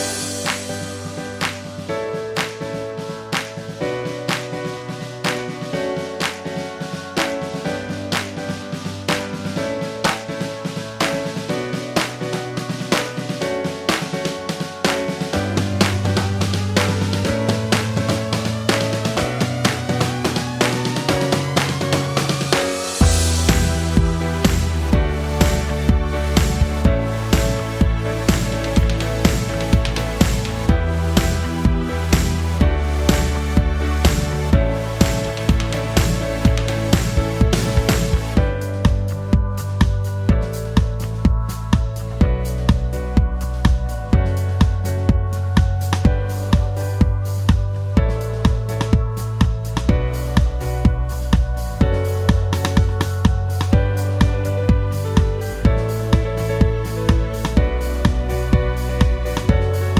Minus Main Guitar For Guitarists 3:59 Buy £1.50